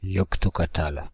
Ääntäminen
Ääntäminen Paris: IPA: [jɔk.tɔ.ka.tal] Tuntematon aksentti: IPA: /jɔk.to.ka.tal/ Haettu sana löytyi näillä lähdekielillä: ranska Kieli Käännökset englanti yoctokatal Suku: m .